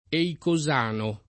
eicosano [ eiko @# no ]